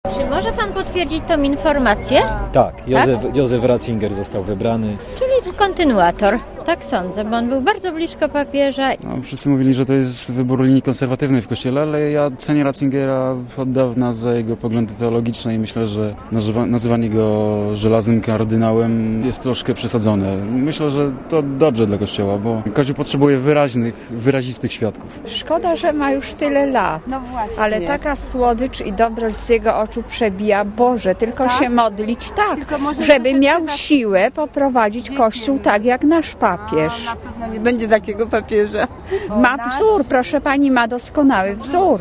* Posłuchaj, co o nowym papieżu mówią mieszkańcy Krakowa*